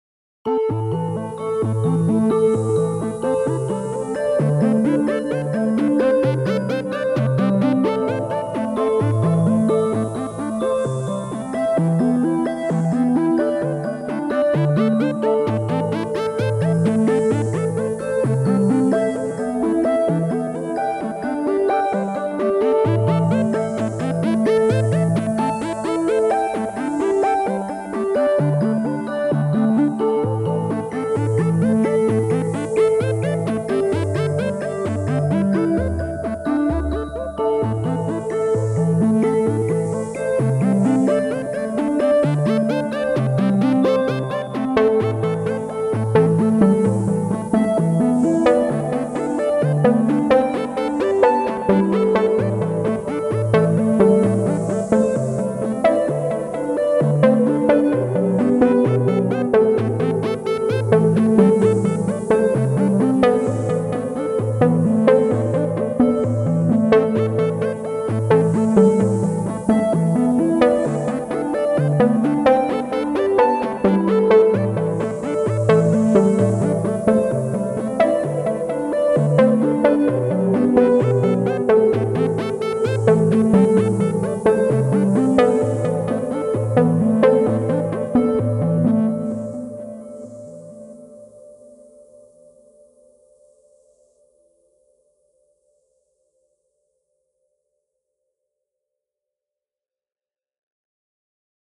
Slow-paced synth driven track for puzzle.